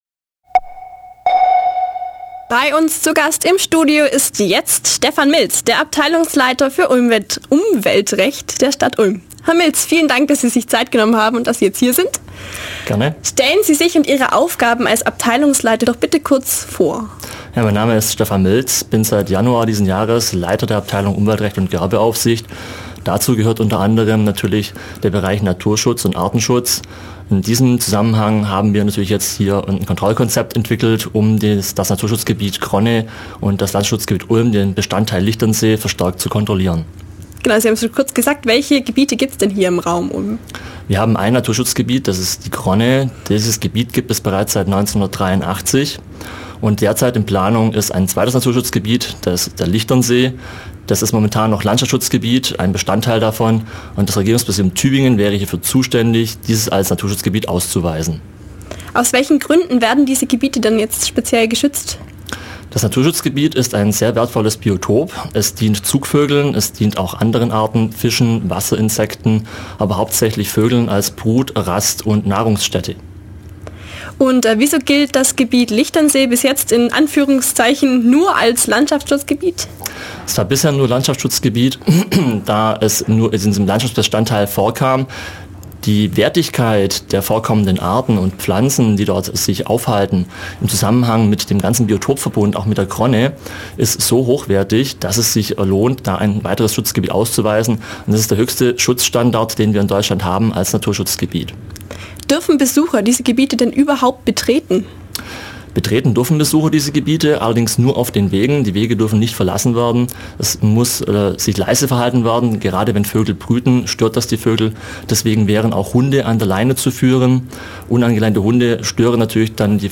Radio